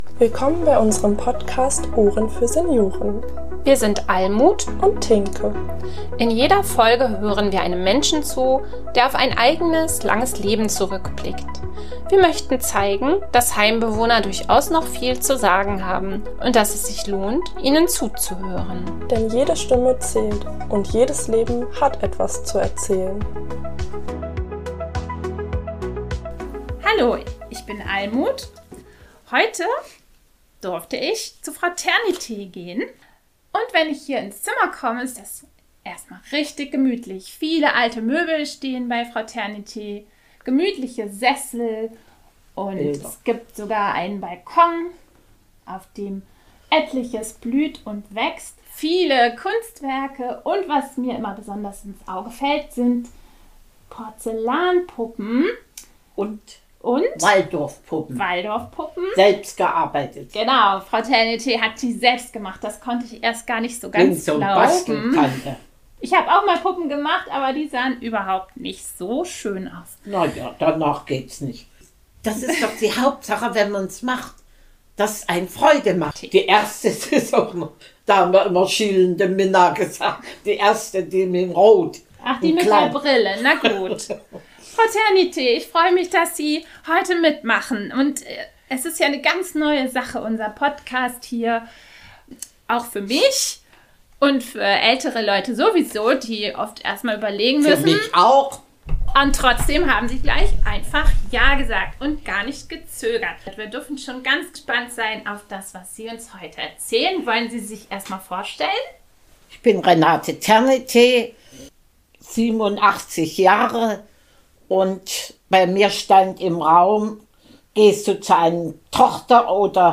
Lebensbericht
voller Schwung und Erzählfreude